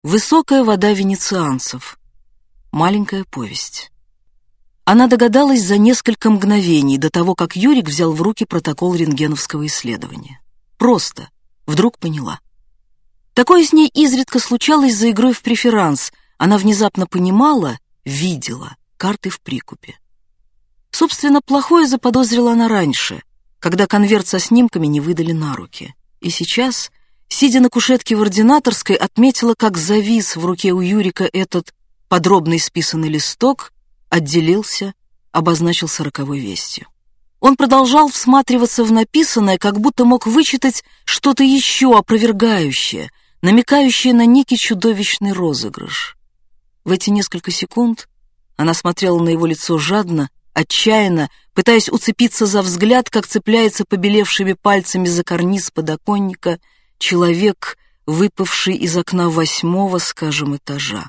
Aудиокнига Высокая вода венецианцев Автор Дина Рубина Читает аудиокнигу Дина Рубина.